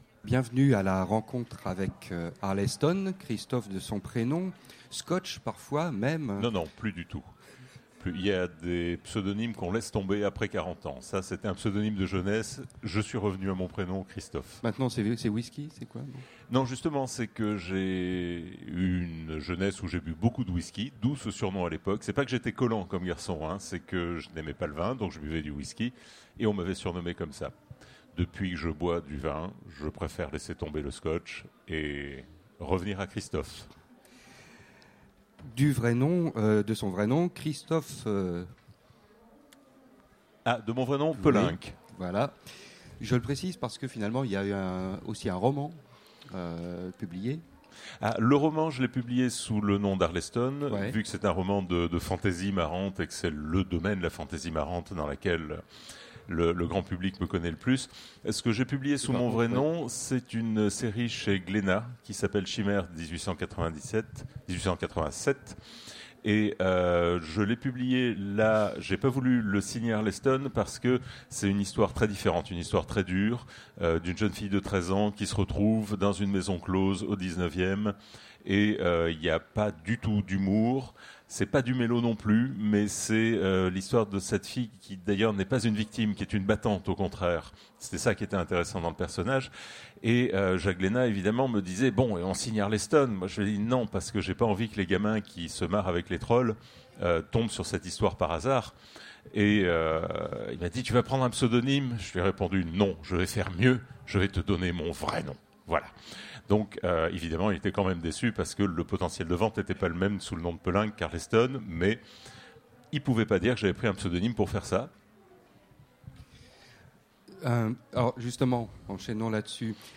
Utopiales 2016 : Rencontre avec Arleston
- le 31/10/2017 Partager Commenter Utopiales 2016 : Rencontre avec Arleston Télécharger le MP3 à lire aussi Christophe Arleston Genres / Mots-clés Rencontre avec un auteur Conférence Partager cet article